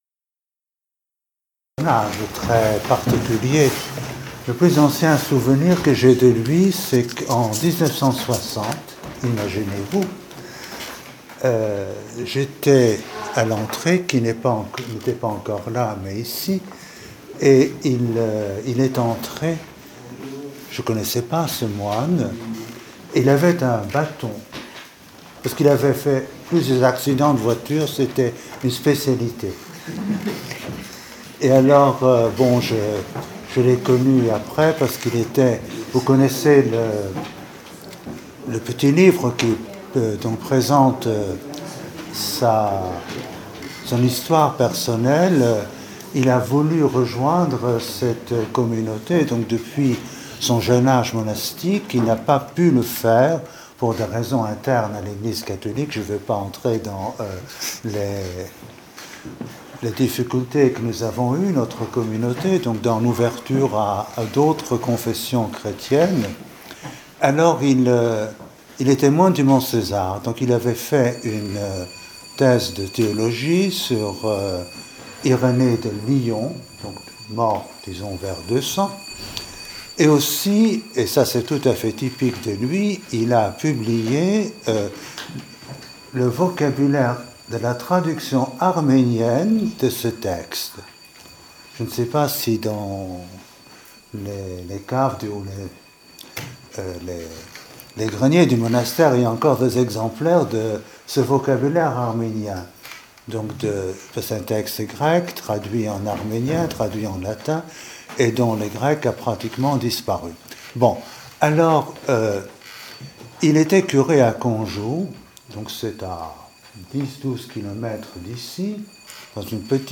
Témoignage sur le père Bruno Reynders | Chrétiens Orthodoxes en Dialogue avec les Juifs